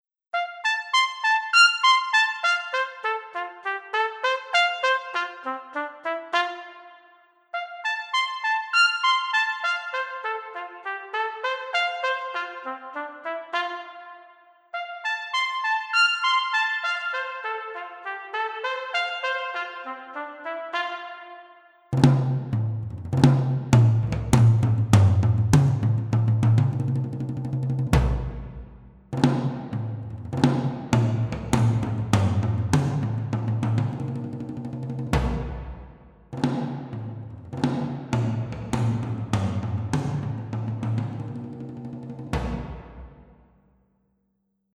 Creating Depth using the Hybrid Reverb.
...OK, with a little support of EQs and Powerpanners....